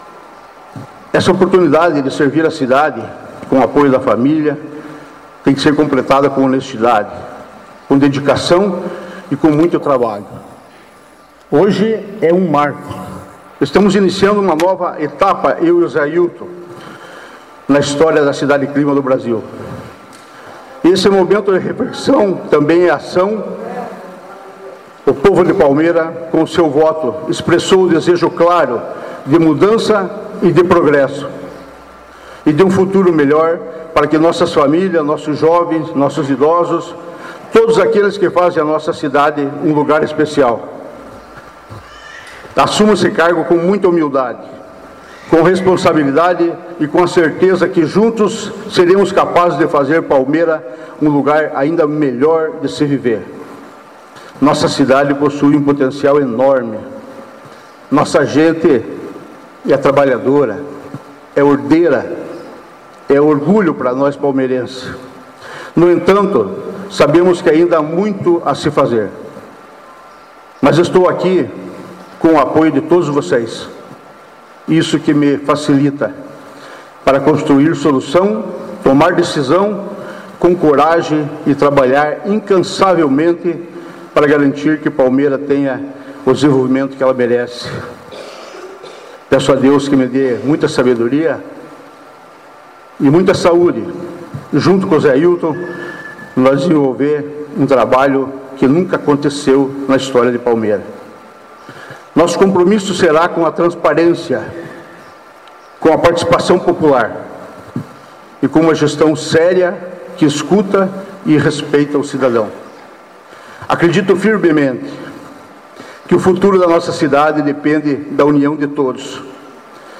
Na manhã do primeiro dia de 2025, no Cine Teatro Municipal, com lotação completa, aconteceu sessão solene de posse dos eleitos em 6 de outubro para a Prefeitura e a Câmara Municipal de Palmeira.
Altamir Sanson ainda falou sobre a quarta oportunidade que tem de ser prefeito de Palmeira e projetou objetivos e metas de seu governo, que teve início, efetivamente, na quinta-feira (2).